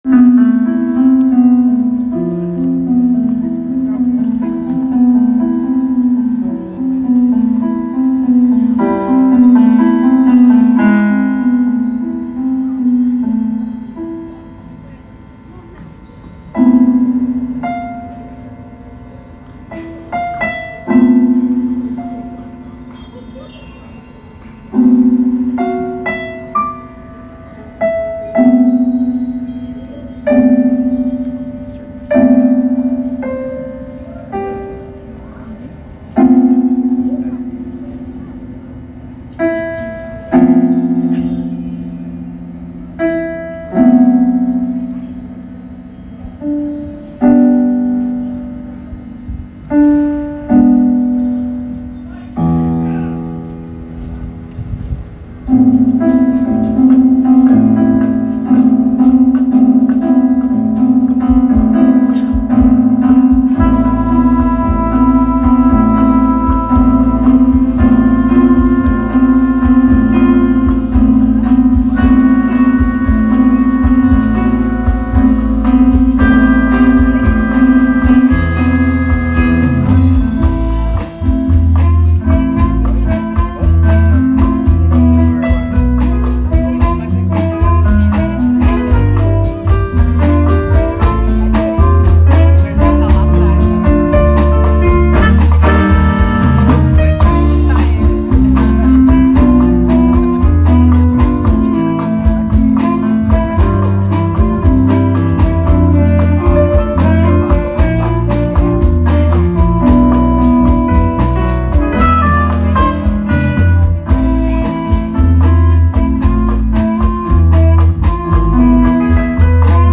Parte da Orquestra “Do Iguaçu ao Pajeú” da banda ENSAX, no último sábado (01) no Fringe